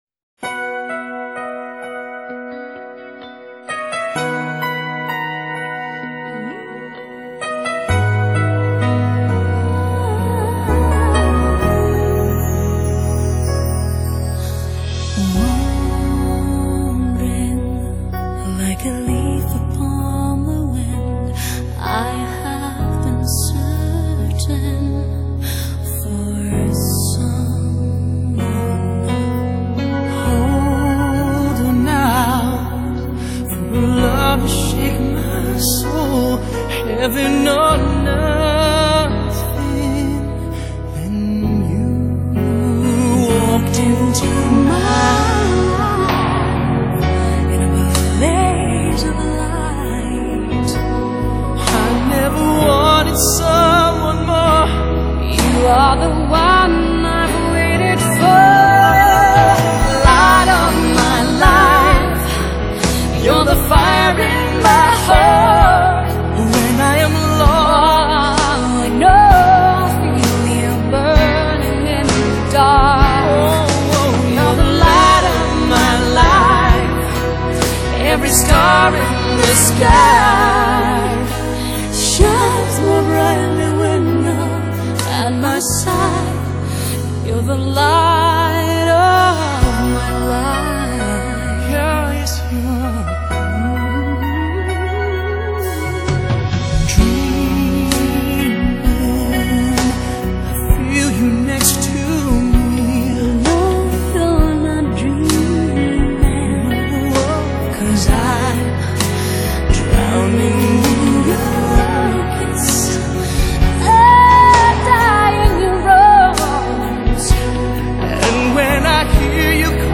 Pop, Adult Contemporary, Pop Rock